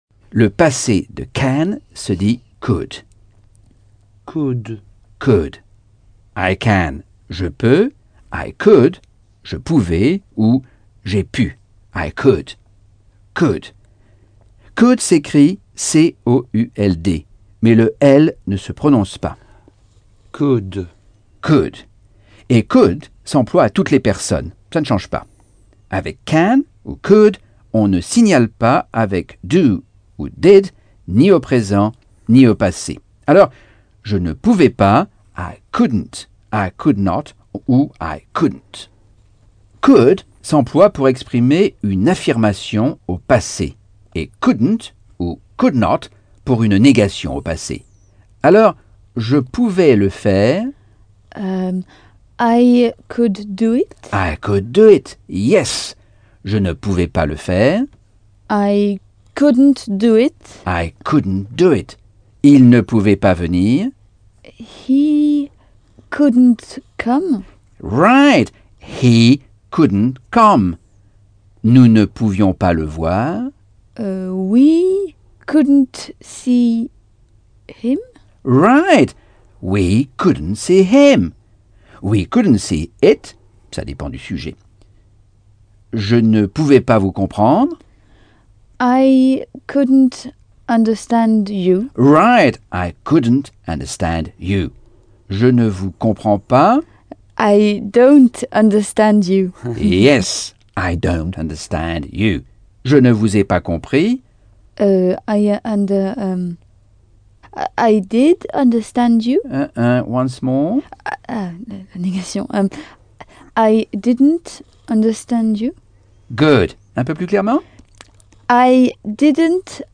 Leçon 4 - Cours audio Anglais par Michel Thomas - Chapitre 6